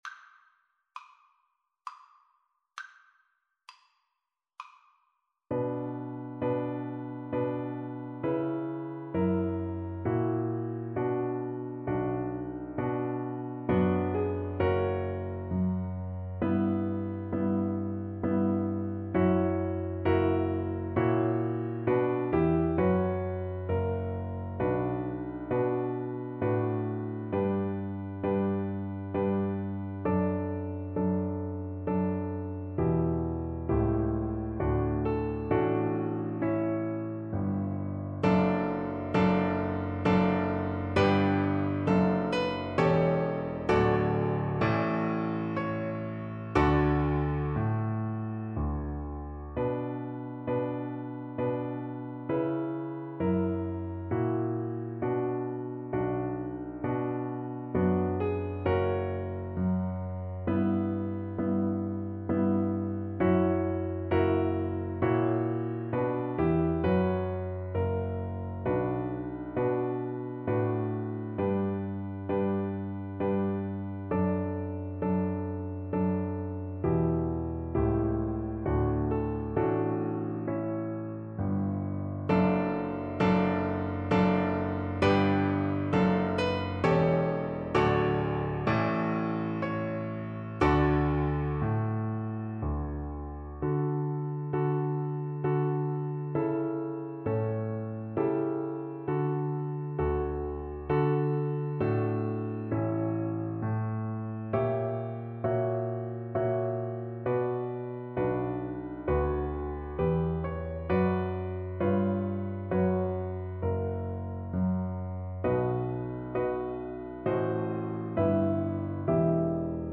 Cello
B minor (Sounding Pitch) (View more B minor Music for Cello )
Largo con espressione =66
3/4 (View more 3/4 Music)
Classical (View more Classical Cello Music)
tartini_sarabanda_VLC_kar1.mp3